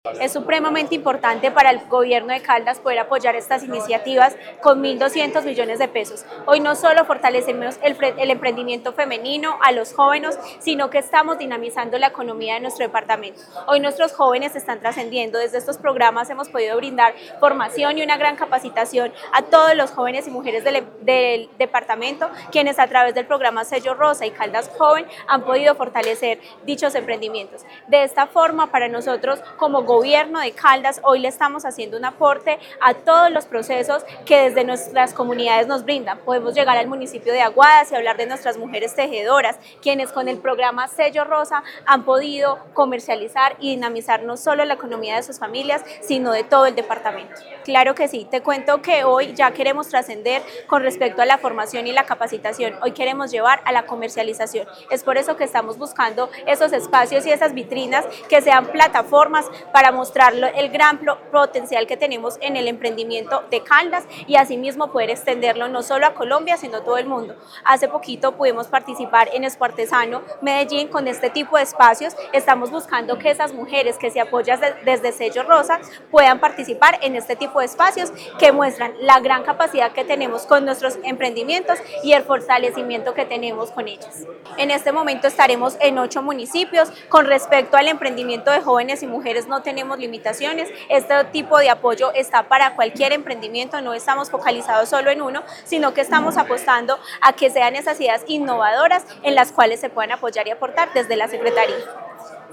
Tania Echeverry Rivera, secretaria de Desarrollo, Empleo e Innovación de Caldas.